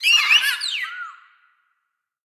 Sfx_creature_seamonkey_gimme_02.ogg